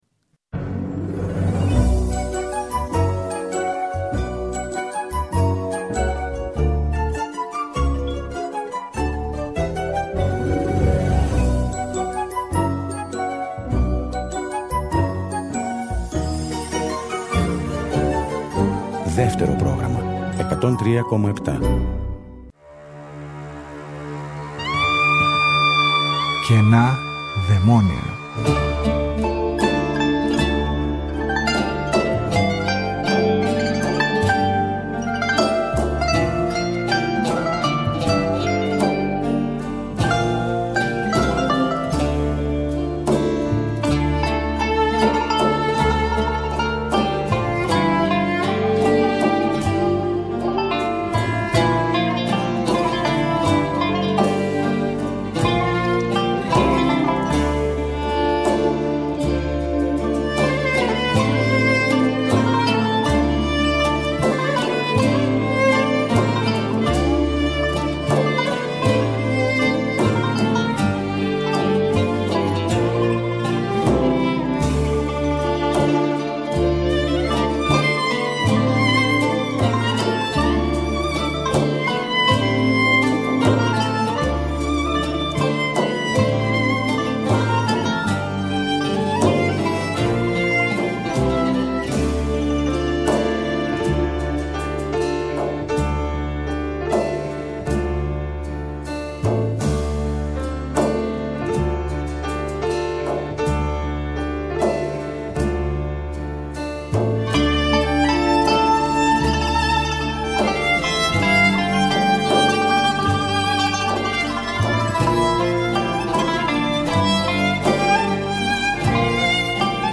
Ηχογραφήσεις ελληνικών λαϊκών τραγουδιών και όχι μόνο, στο studio C και Β με την Big Band της Ελληνικής Ραδιοφωνίας το 1996 και 1997.